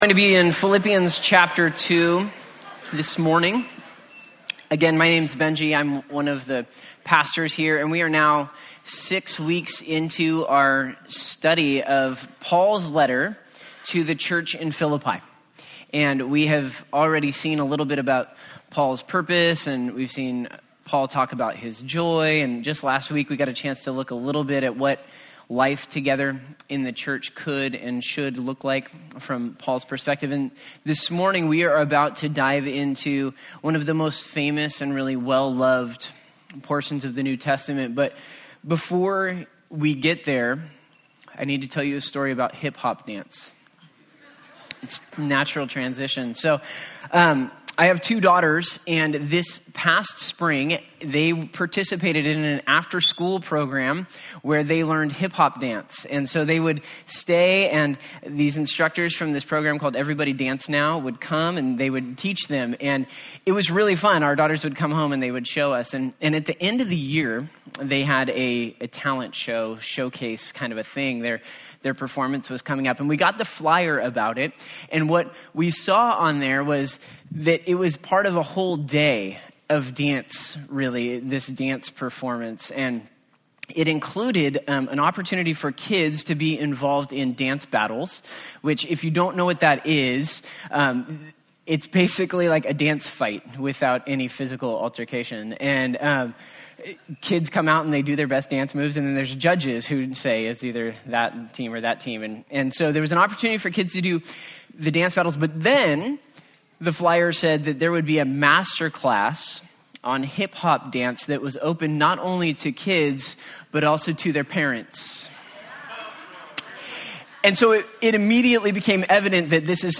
Philippians 2:1-11 Service Type: Sunday Topics